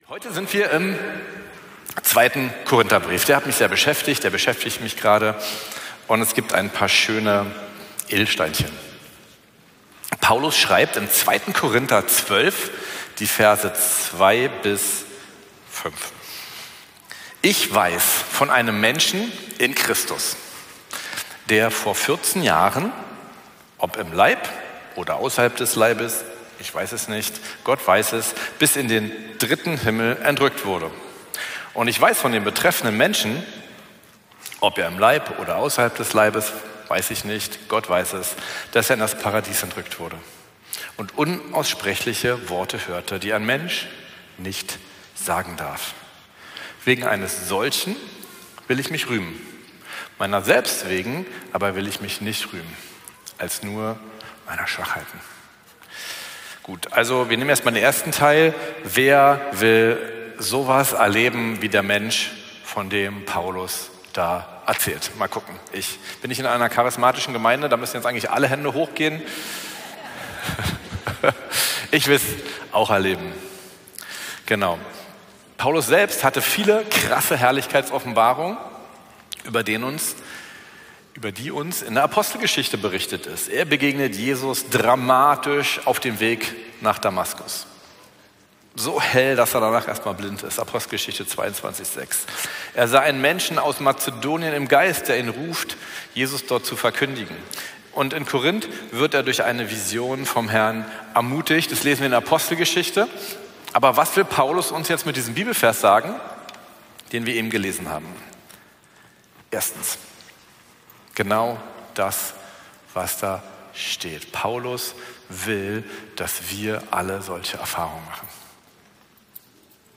Predigten von Veranstaltungen der Gemeinde auf dem Weg, Berlin